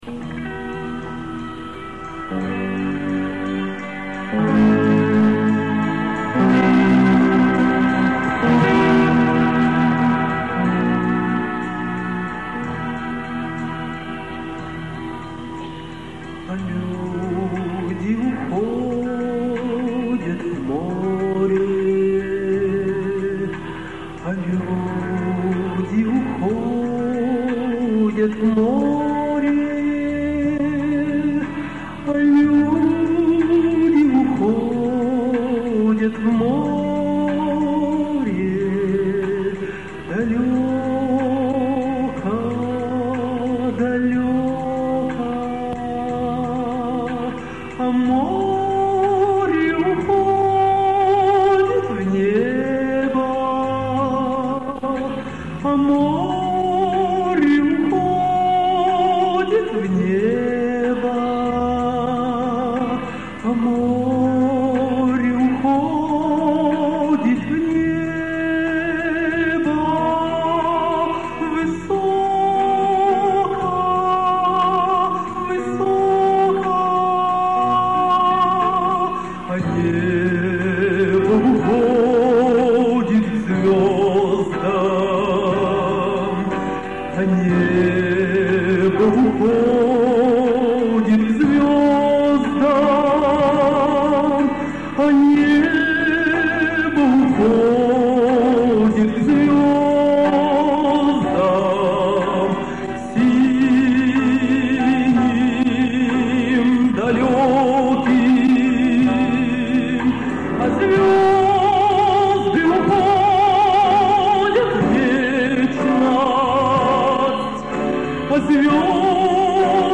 концертная запись
вполне себе приемлемого качества